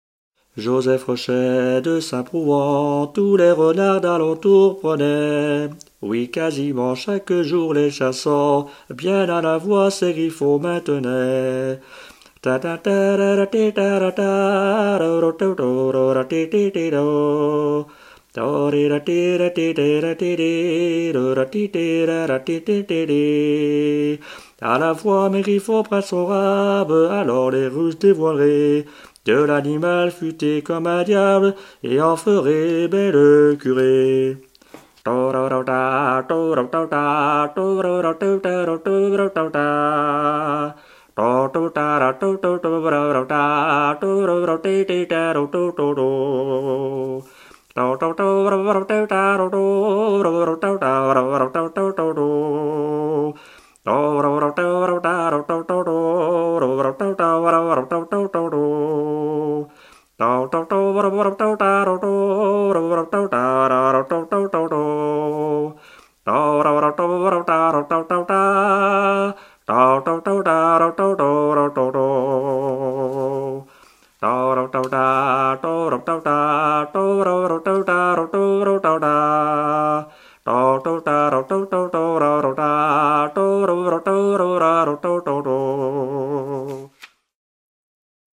circonstance : vénerie
Pièce musicale éditée